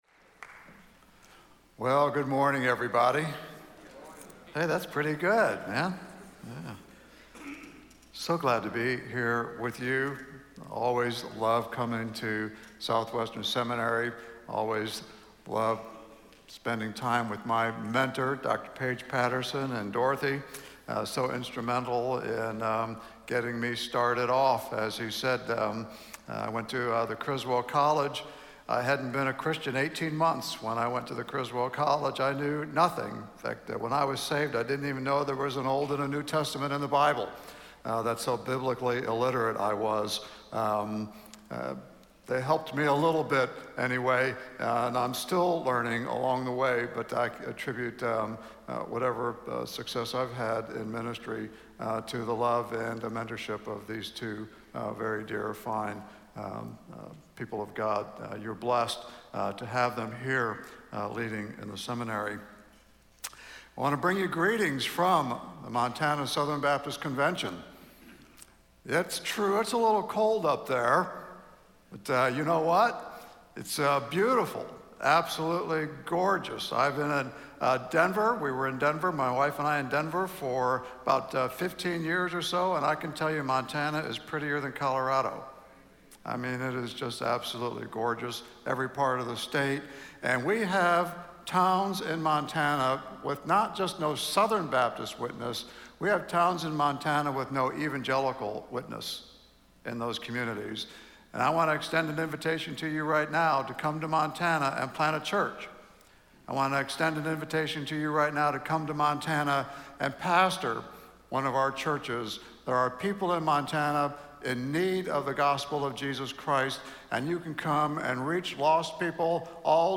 speaking on Luke 10:25-37 in SWBTS Chapel on Thursday November 2, 2017